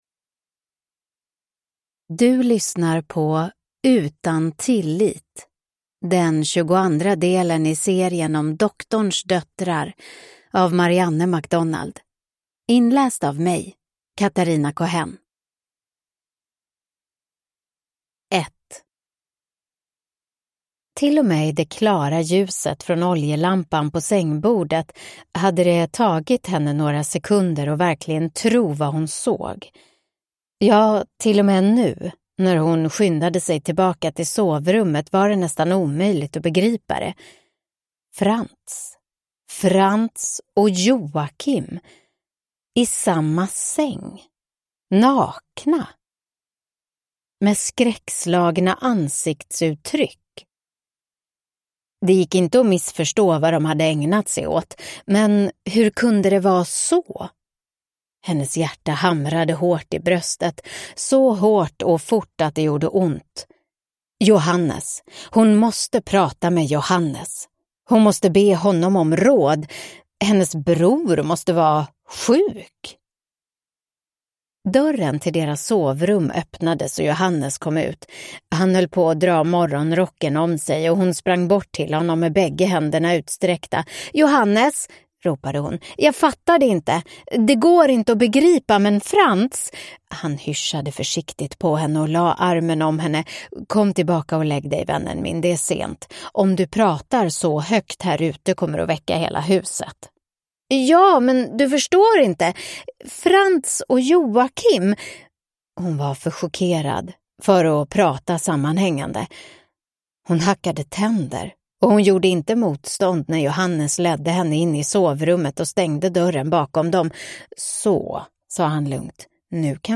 Utan tillit (ljudbok) av Marianne MacDonald